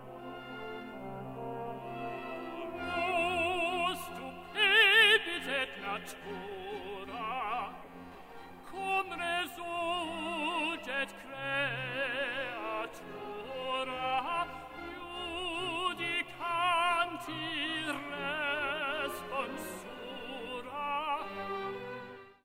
Extracts from live recordings
tenor